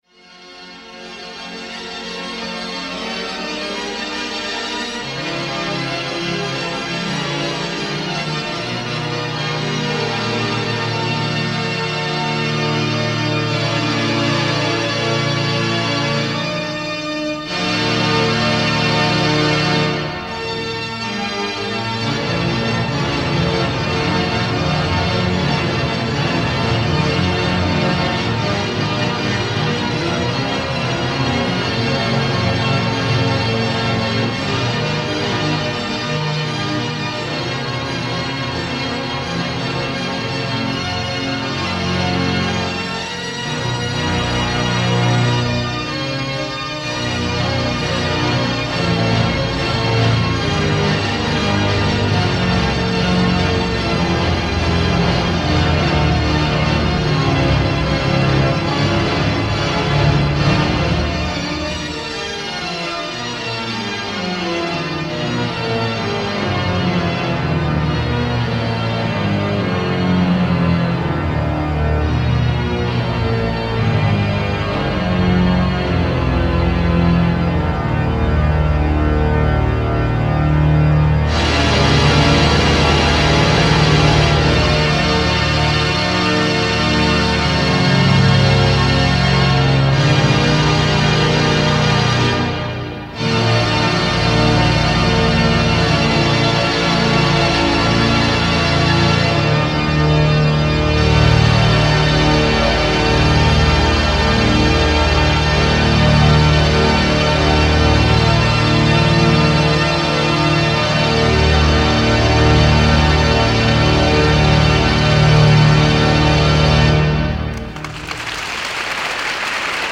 de sus dos conciertos en el gran órgano del Palacio Nacional de Montjuich.